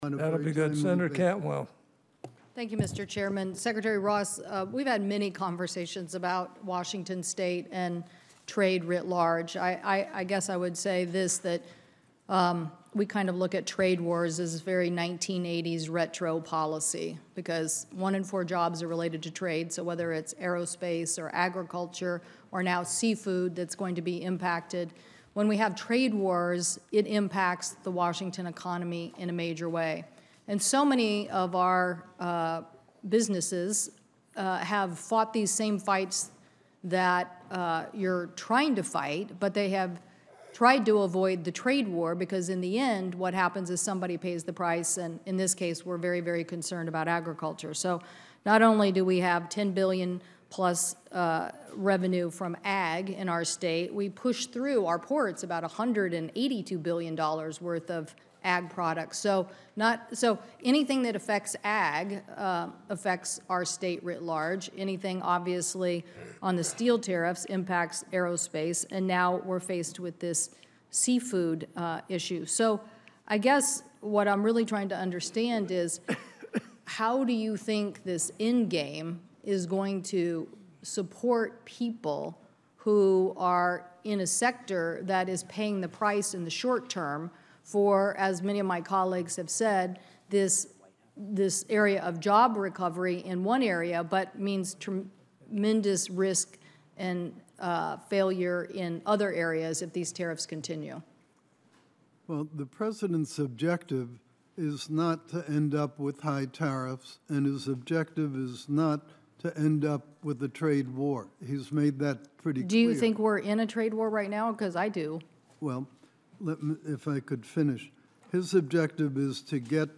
audio-finance-hearing-with-secretary-ross&download=1